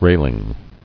[rail·ing]